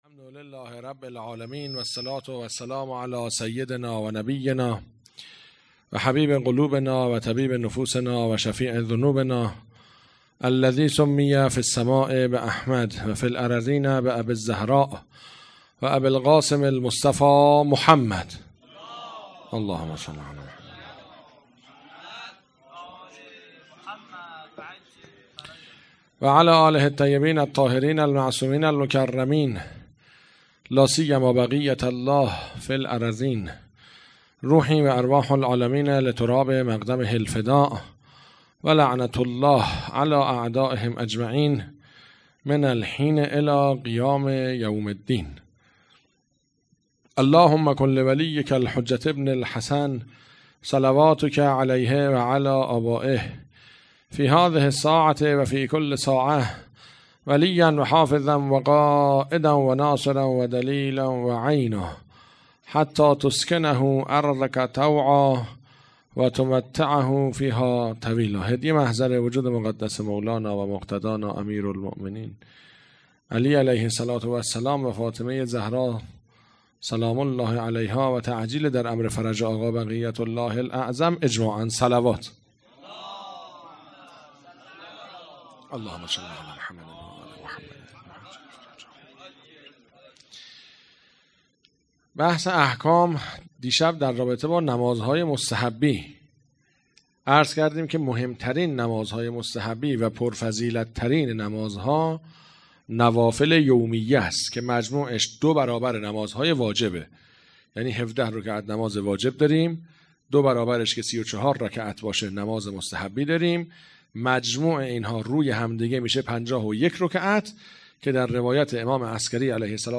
خیمه گاه - هیئت عقیله بنی هاشم سبزوار - جلسه احکام شب دوم مراسم شهادت حضرت ام البنین سلام الله علیها ۱۴۰۳